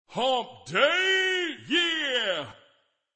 Hump Day Camel Laugh